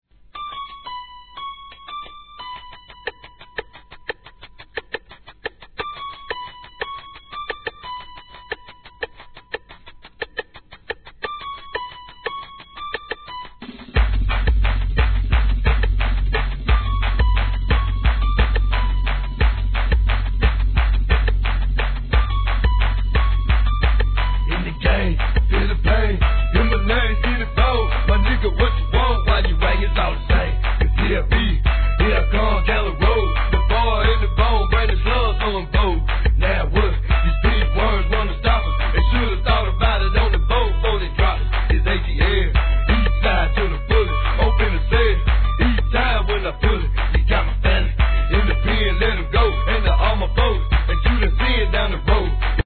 G-RAP/WEST COAST/SOUTH
切なささえ感じさせるようなシンセのLOOPであしらったトラックで披露する、SOUTH直球の超ドマイナーRAP!!!